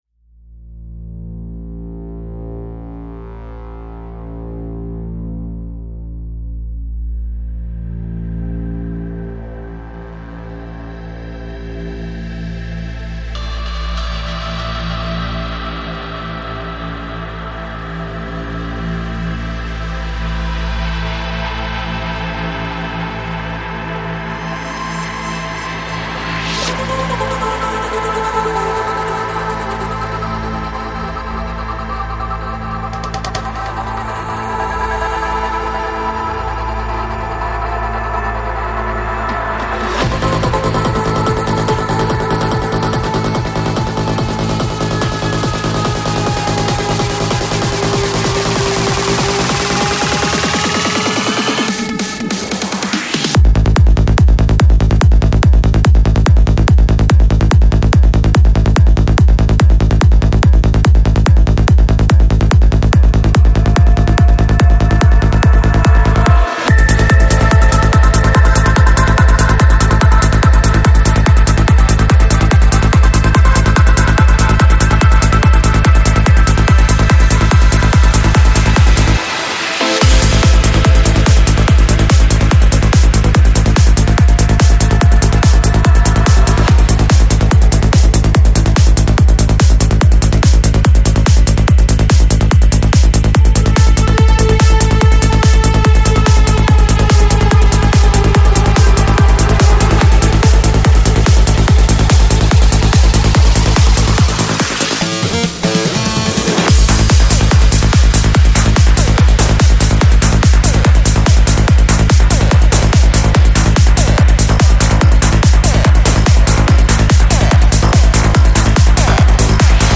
Жанр: Psychedelic
Psy-Trance